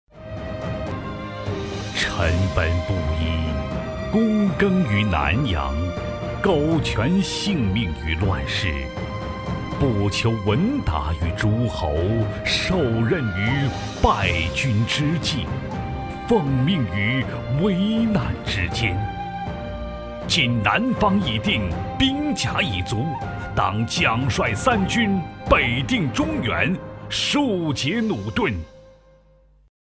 男60-朗诵配音《出师表》-感人大气
男60-朗诵配音《出师表》-感人大气.mp3